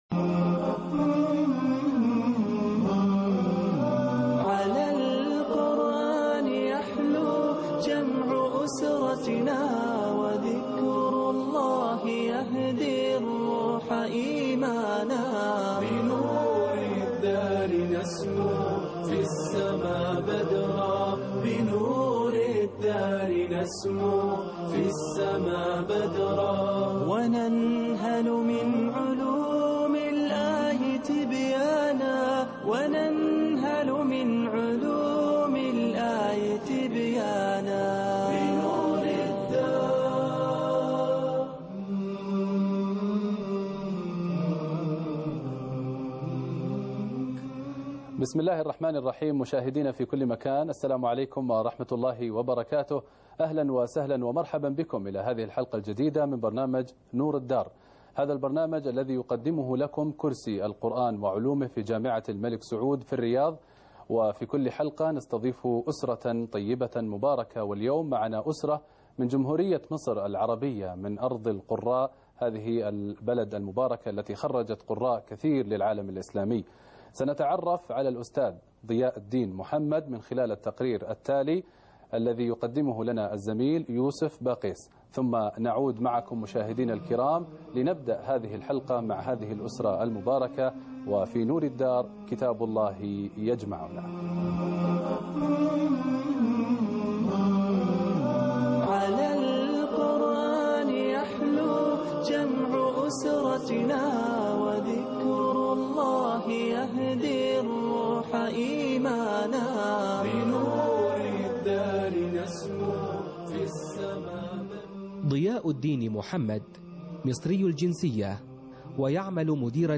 لقاء مع اسره من مصر -الجزء العاشر من القرآن الكريم -نور الدار - قسم المنوعات